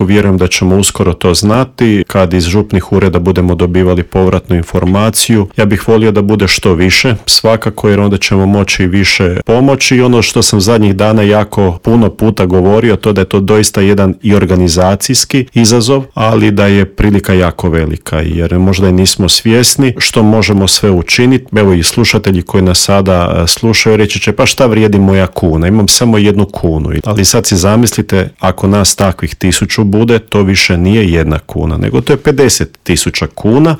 O projektu smo u Intervjuu MS-a razgovarali